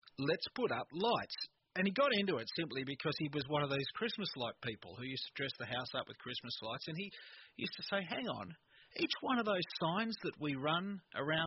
Tunnel Effect with exported files only
The problem is when I export the file to an MP3 file and then play that file with Windows Media Player, I get the tunnel effect again.
The sample file uses a high compression rate of 32 kbps - That will give a small file size, but more damage.
It’s a voice recording, so while the amount of compression is quite high it’s not way over the top.